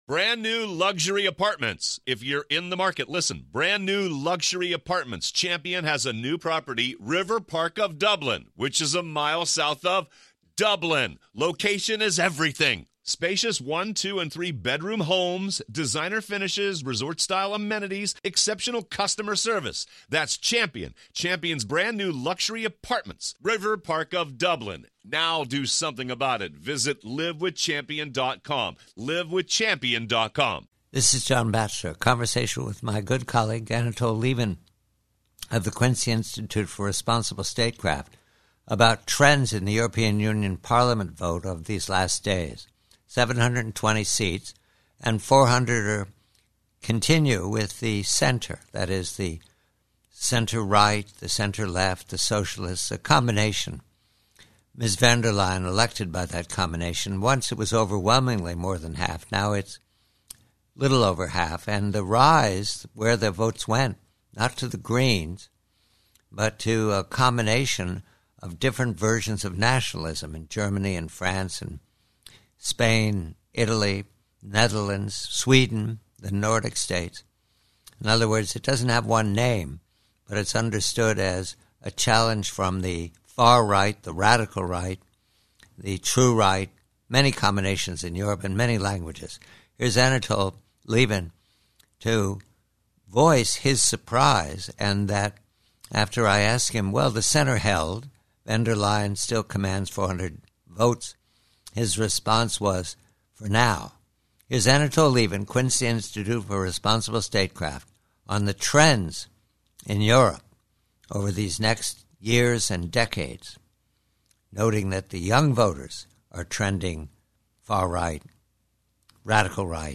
PREVIEW: EU VOTE: Conversation with colleague Anatol Lieven of the Quincy Institute re the nationalist trend in the EU vote - and the surprise that liberal-minded Sweden now features a nationalist party - and that the future of the EU appears rightward to